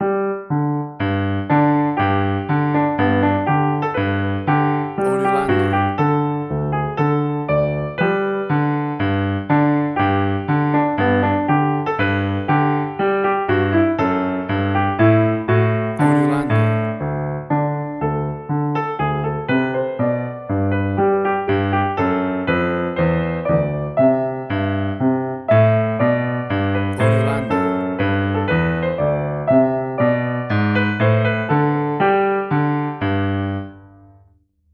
played on a Grand Piano
Tempo (BPM): 120